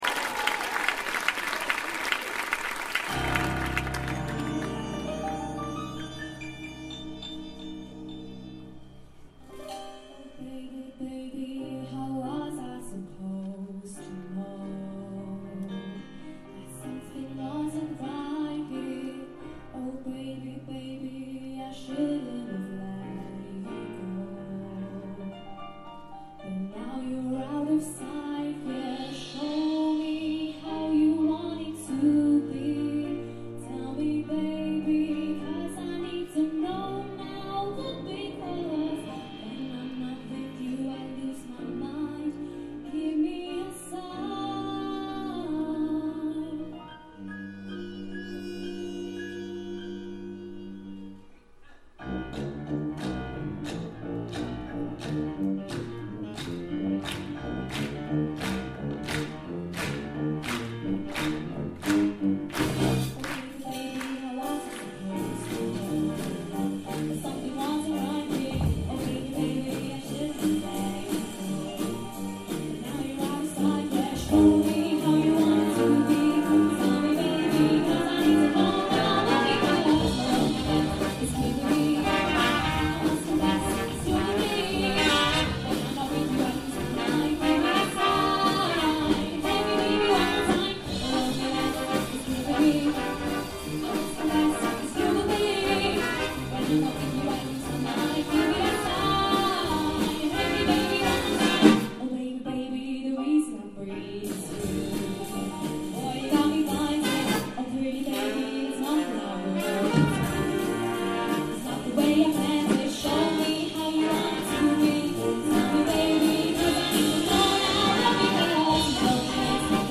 Trad Band